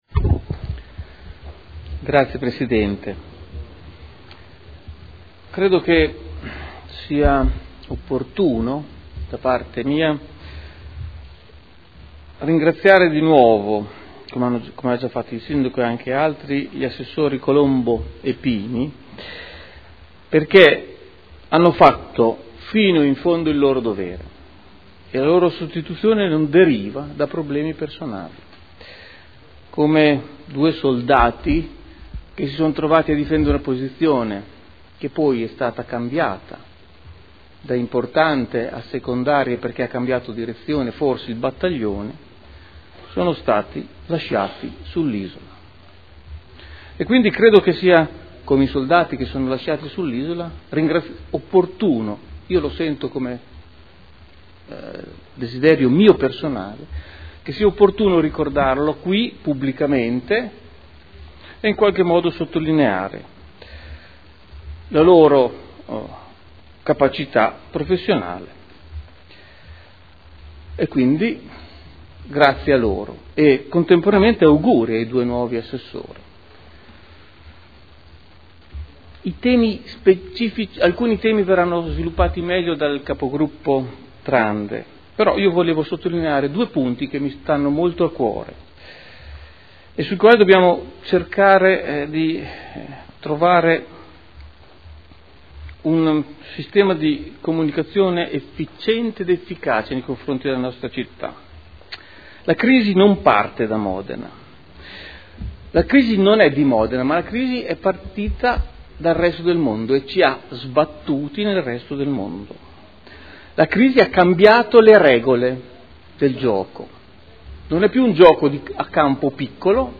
Luigi Alberto Pini — Sito Audio Consiglio Comunale